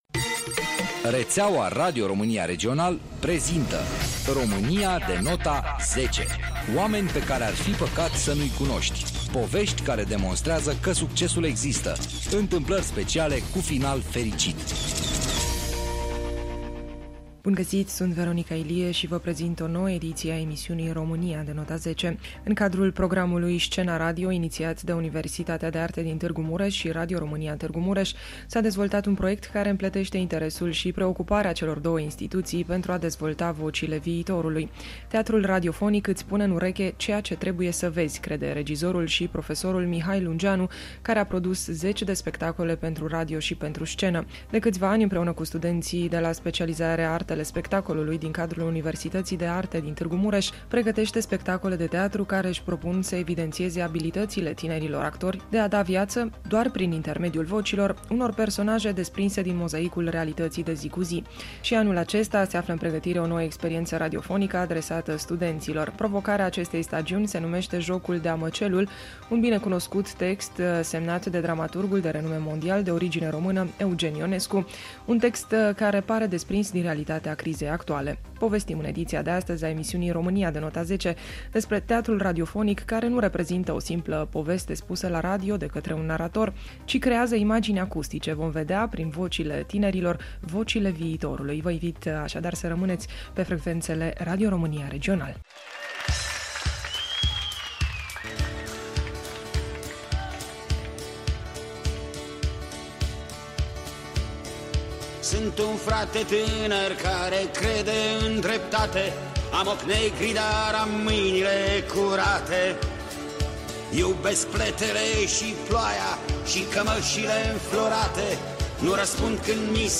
Romania-de-nota-10_Tg.Mures_proiect-teatru-radiofonic.mp3